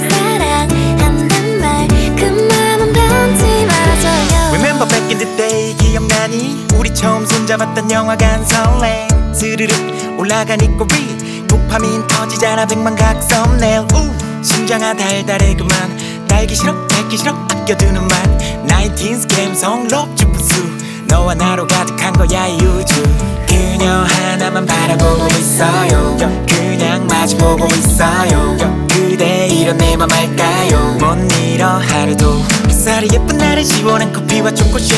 K-Pop Pop Hip-Hop Rap Korean Hip-Hop
Жанр: Хип-Хоп / Рэп / Поп музыка